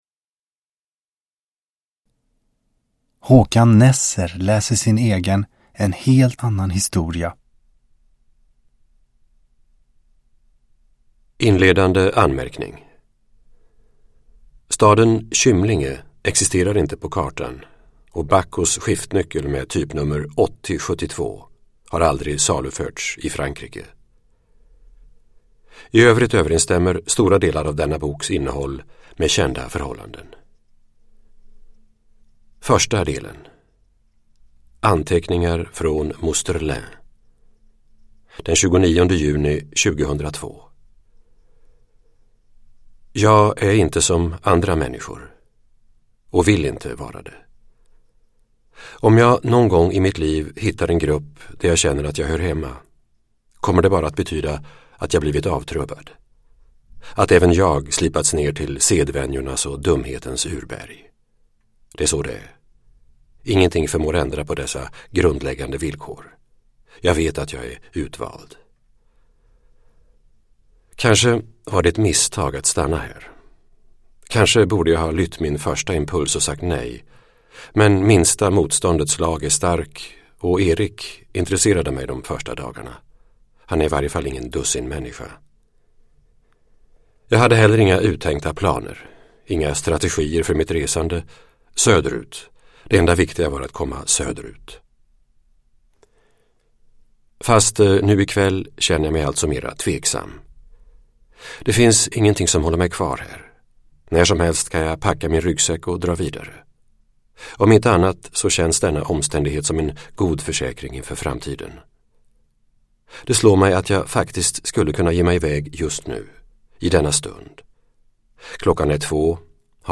Uppläsare: Håkan Nesser
Ljudbok